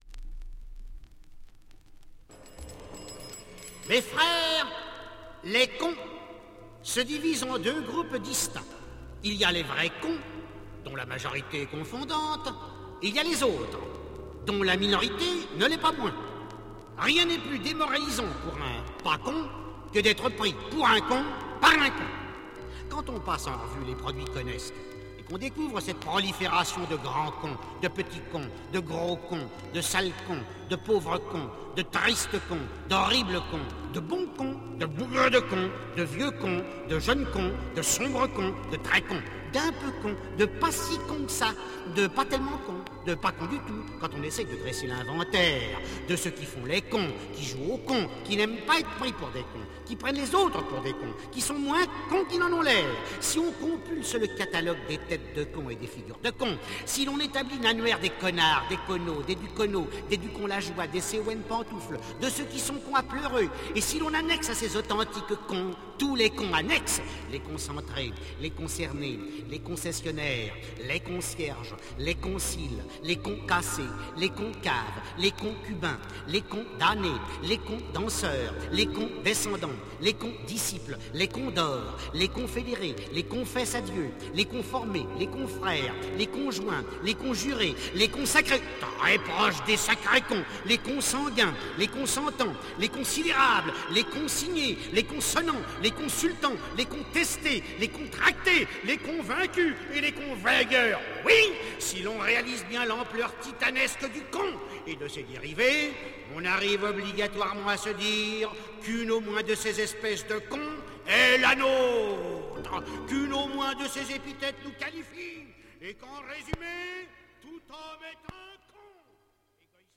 Psych funk Spoken words LP